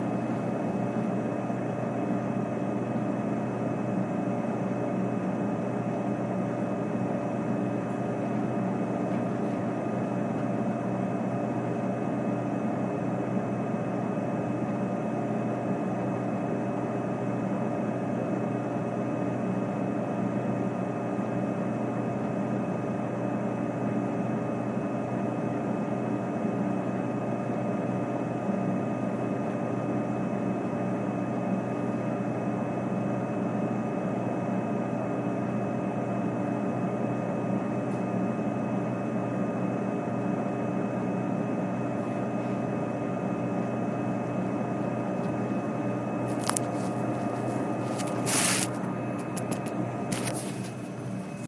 Sounds heard: Rumbling of Coca Cola machine, quiet lights buzzing.
This entry was posted in Field Recording .